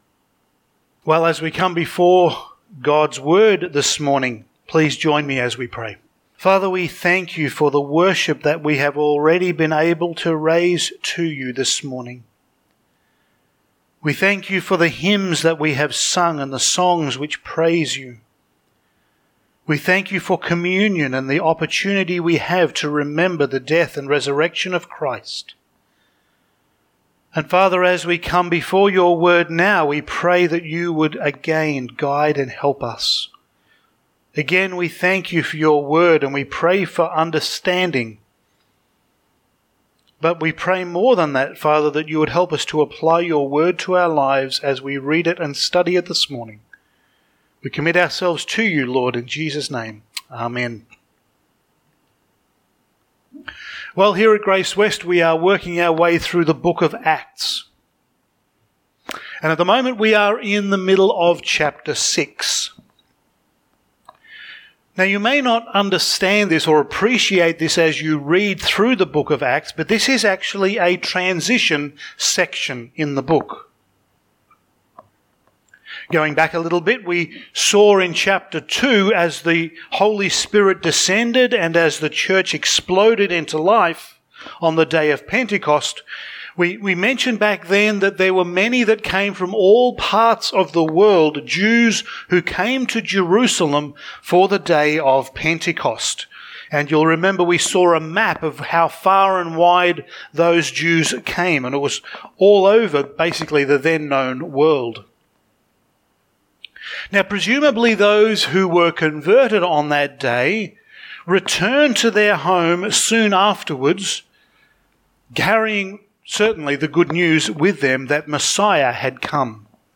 Passage: Acts 6:8-15 Service Type: Sunday Morning